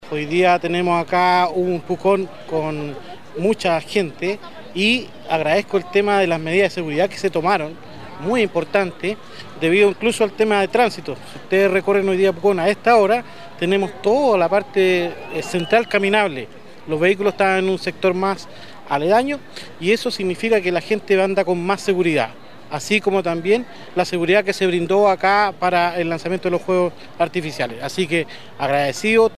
De hecho, el concejal de la comuna, Claudio Cortez, reconoció la labor.
Claudio-Cortez-concejal-valora-la-organizacion-del-evento-.mp3